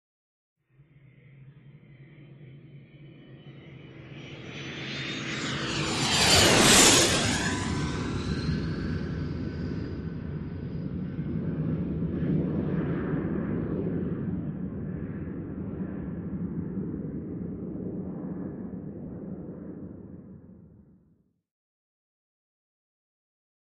Marchetti: By Low; Rumble And Low Whine, Approach, By And Away. Jet.